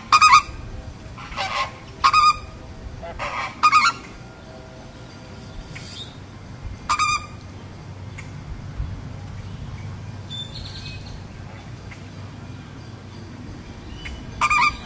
科马奇奥湖的鸟类 2
描述：科马奇奥湖的鸟类是位于意大利波河三角洲的湿地。这里有许多典型的鸟类，如Cavaliere d'italia, avocetta, volpoca, flamingos和许多其他鸟类。
标签： 火烈鸟 海鸥 湿地 鸟类 性质 湖泊 现场记录 意大利
声道立体声